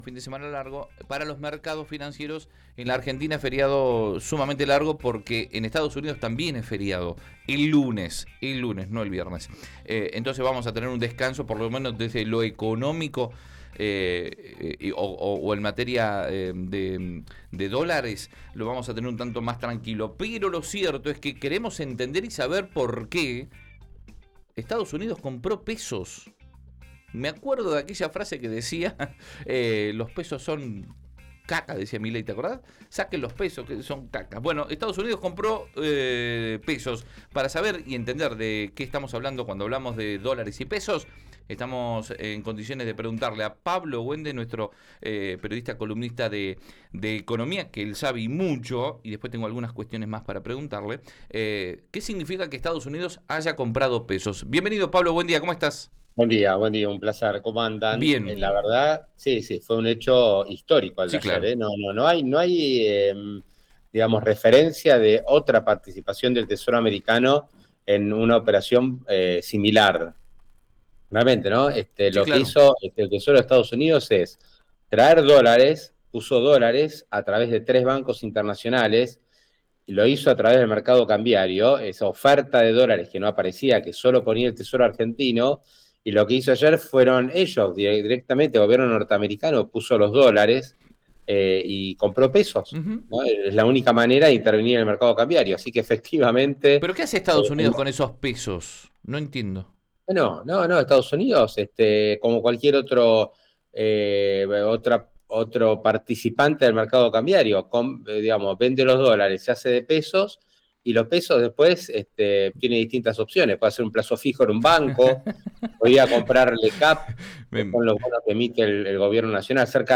Río Negro Radio